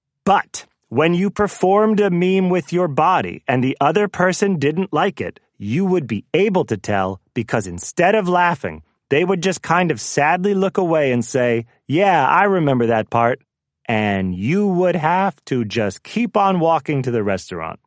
voices/eng/adult/male/JhonMulaney_24khz.wav